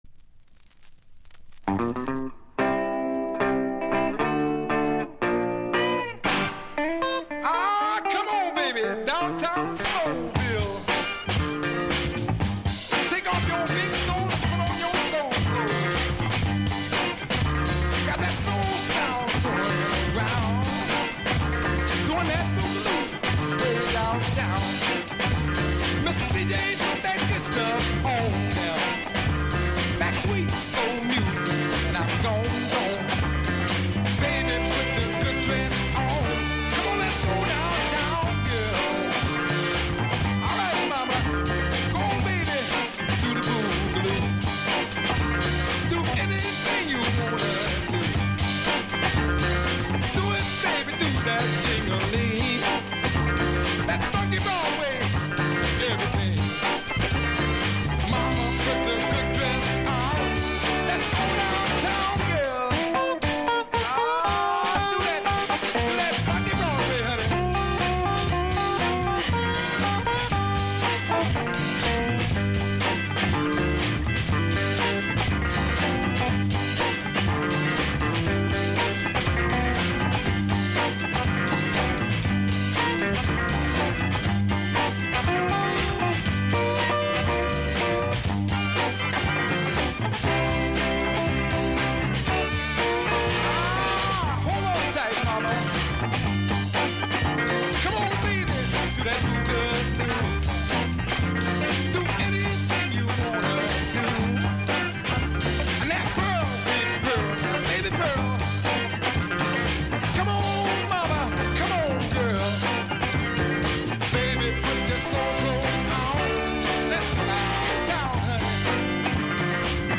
45 Music behind DJ